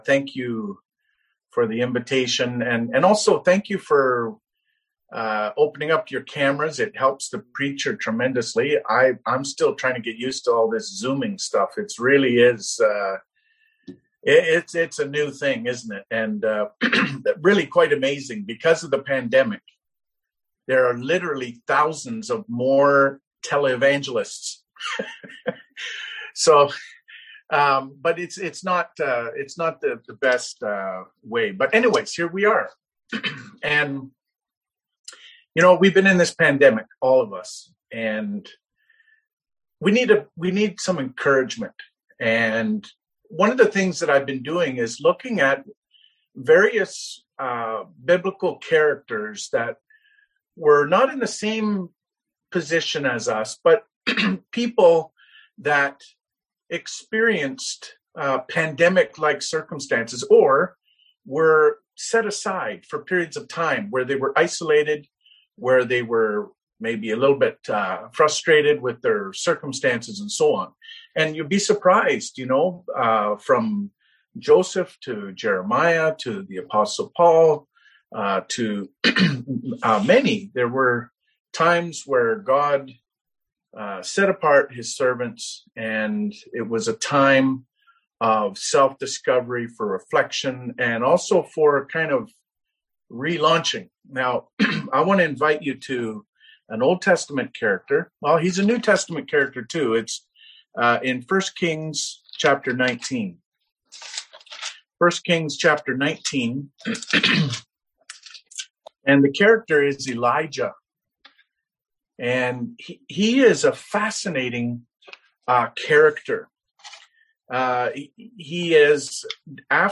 Passage: 1 Kings 19 Service Type: Seminar Topics: Depression , Discouragement